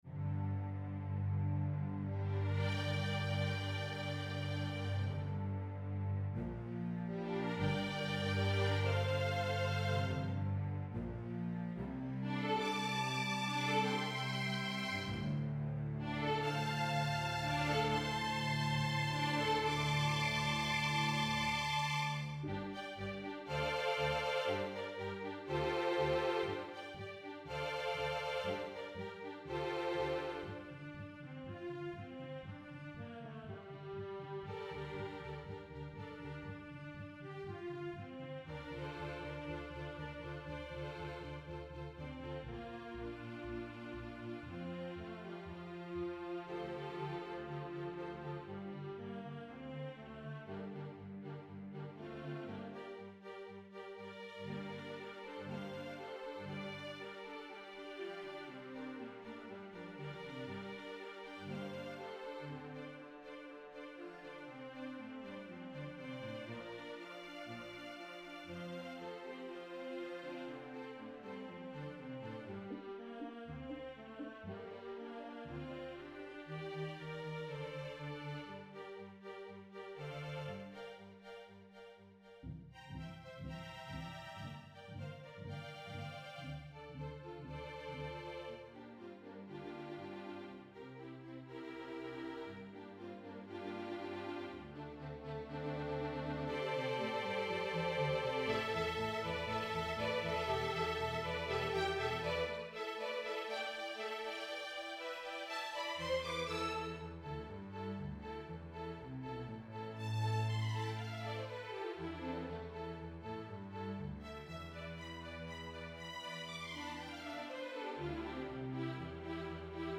for String Orchestra (2017)
Root position chords and consonant sonorities are prevalent.